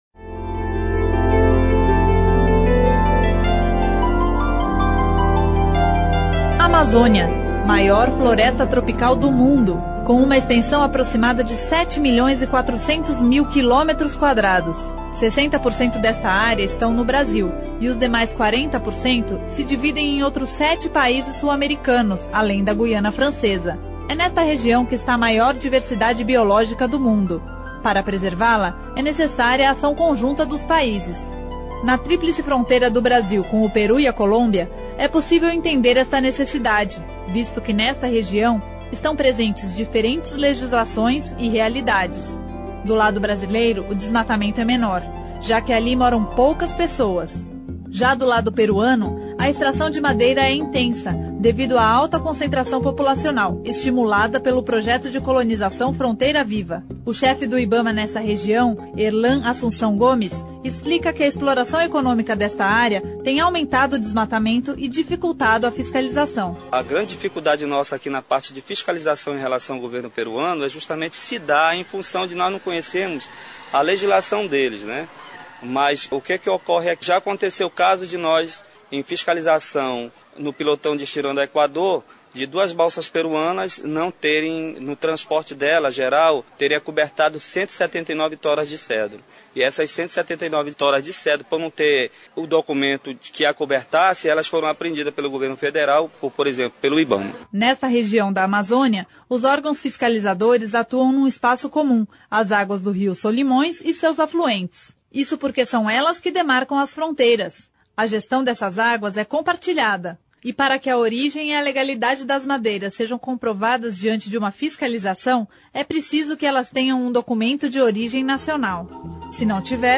Reportagem especial Cidadania nas Fronteiras mostra problemas causados ao meio ambiente nas divisas do país